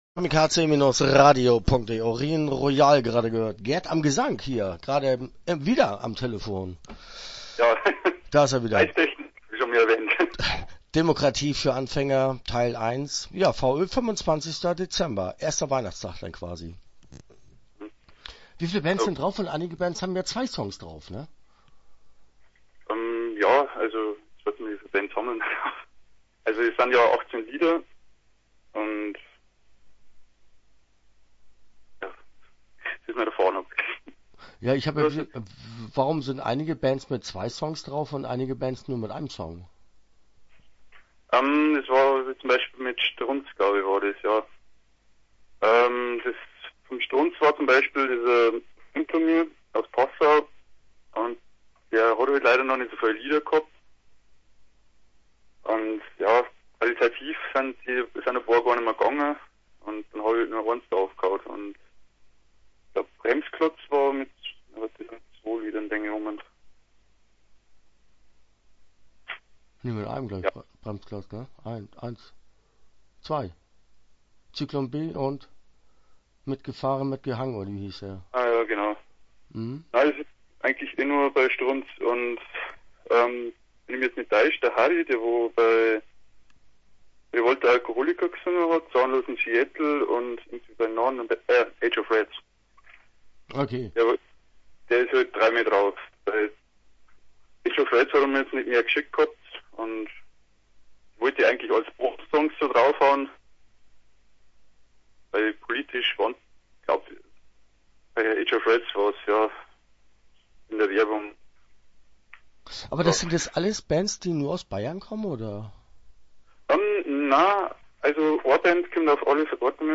Start » Interviews » Sampler "Demokratie für Anfänger"